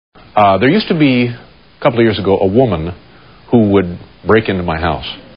Tags: David Letterman Letterman David Letterman clips tv talk show great audio clips